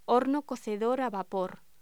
Locución: Horno cocedor al vapor